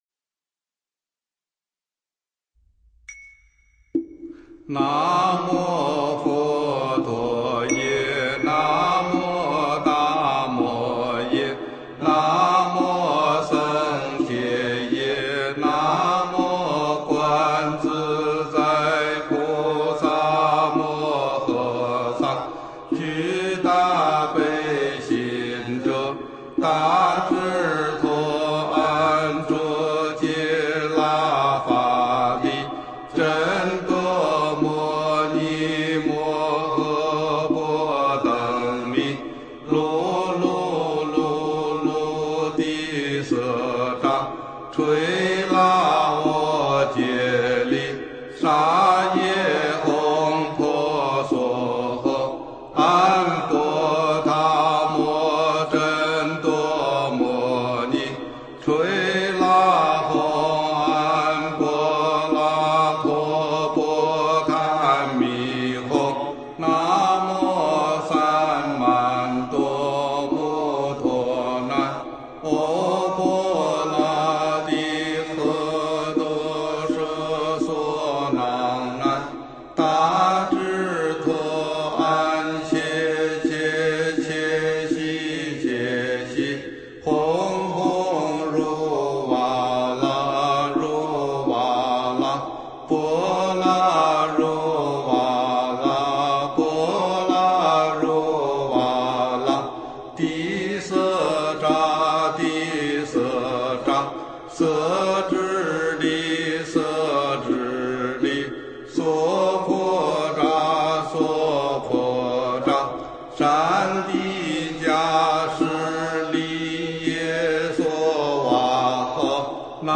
梵呗 | 殊胜的《十小咒》音频、出处、利益功德都在这里了，收藏好随时学习